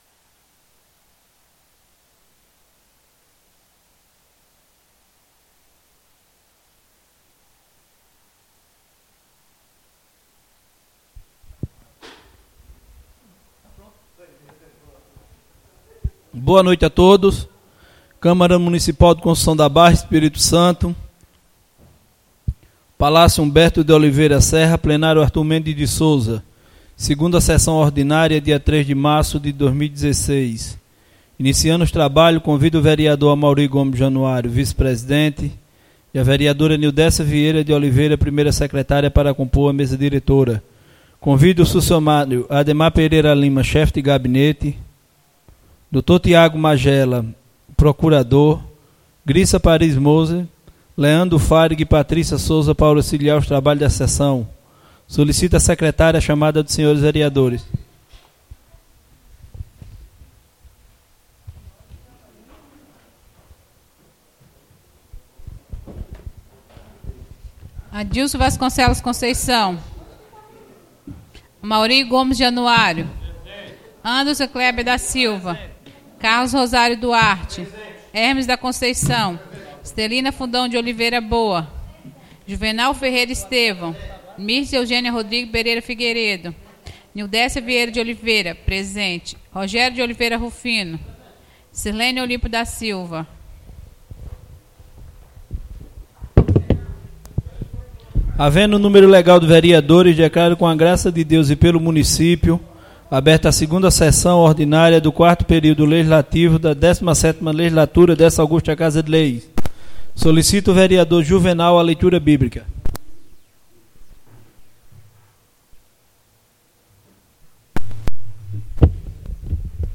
2ª SESSÃO ORDINÁRIA